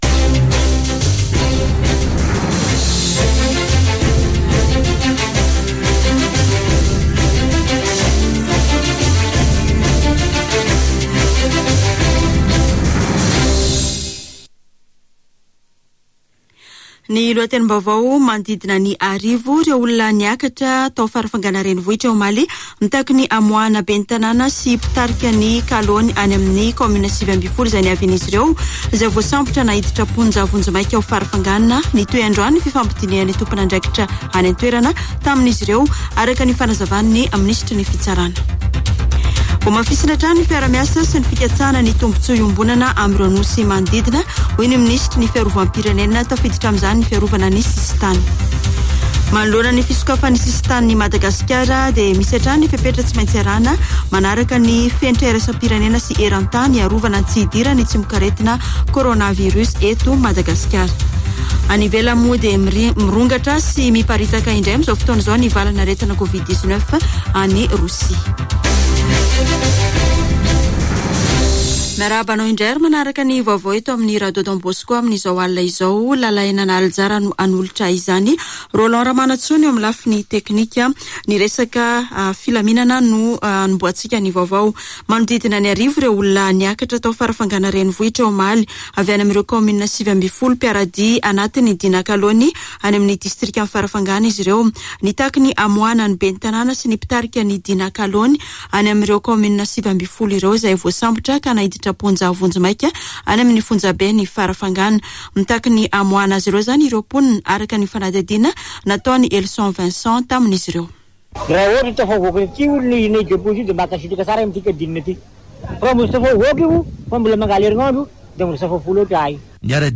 [Vaovao hariva] Alakamisy 28 oktobra 2021